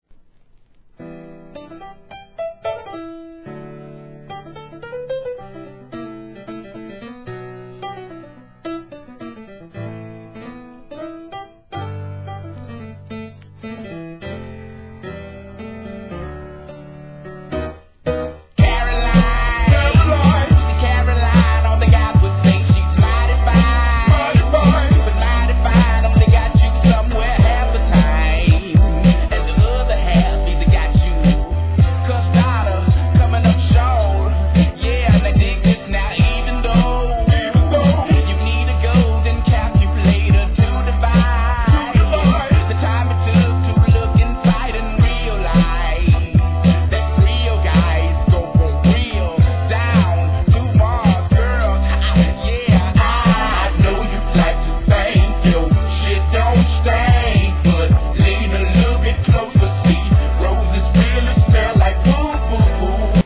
HIP HOP/R&B
哀愁も感じさせるメロディアスなピアノのイントロ、シンセとドラムが加わる本編の気持ちよい旋律と